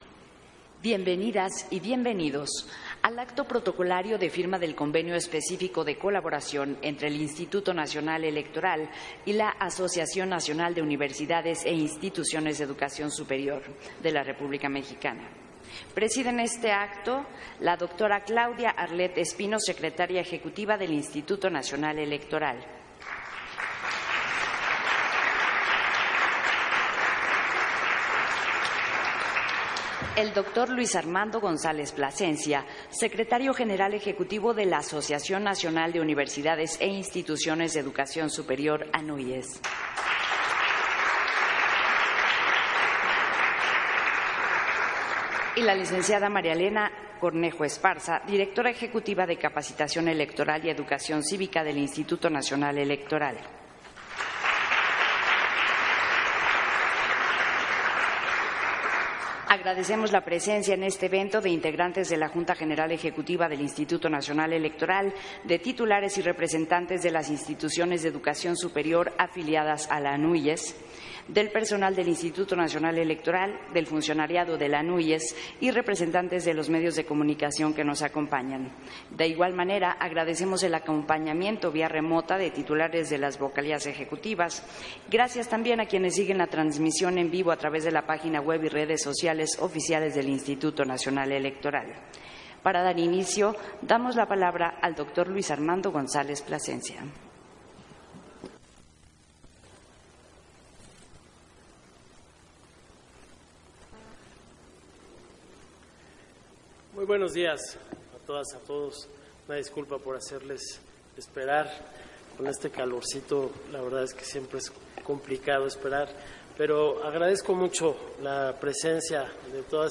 Versión estenográfica del acto protocolario de firma de convenio específico de colaboración INE-ANUIES